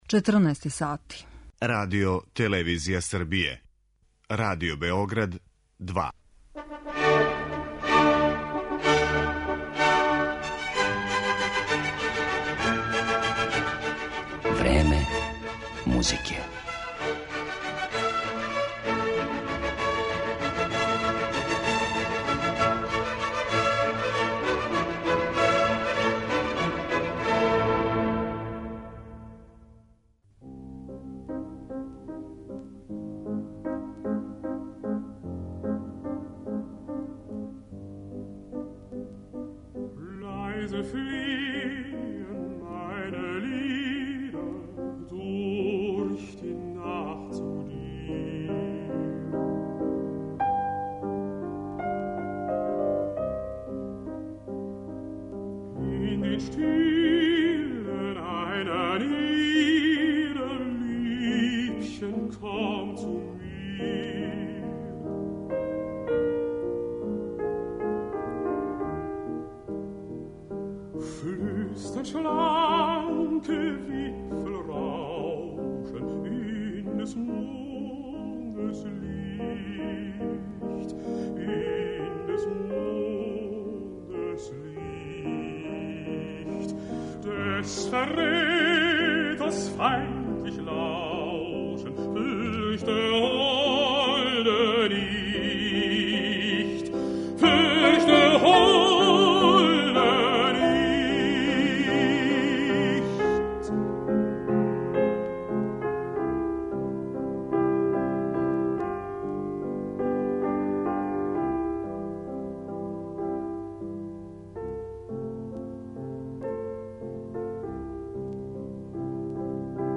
Чувени баритон Дитрих Фишер-Дискау
Када кажемо немачки лид, односно соло-песма, прва асоцијација је Франц Шуберт, а међу извођачима Дитрих Фишер-Дискау, чувени немачки баритон, чија је блистава каријера трајала током целе друге половине ХХ века.